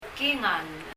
パラオ語 PALAUAN language study notes « dáob 海、大洋 chalk チョーク、白墨 » chair 椅子 kingáll [kiŋal] 英） chair 日） 椅子 Leave a Reply 返信をキャンセルする。